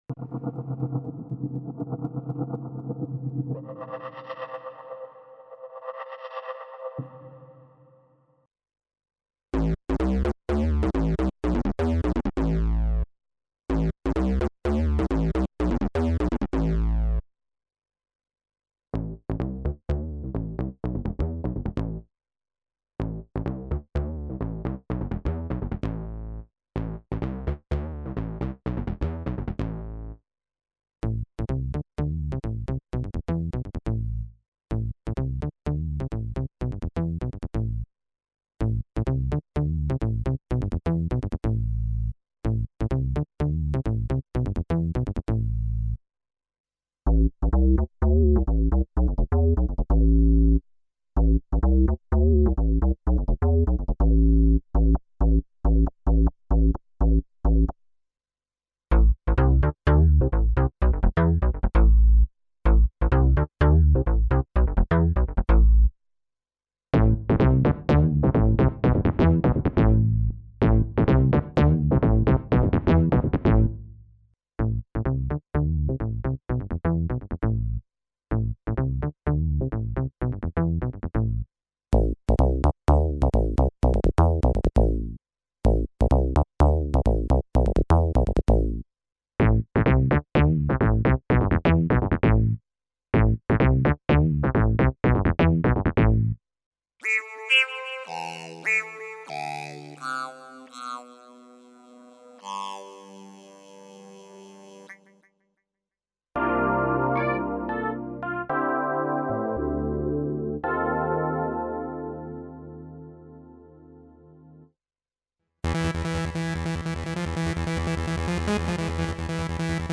Сэмплы 90х - 2000х (Евродэнс, евротранс), VST для эмуляции "того" звука
Этот бас был в куче старых клубных трансовых и хаусовых треков.
Орган из хаус треков конца 90х
Пэд был в куче транса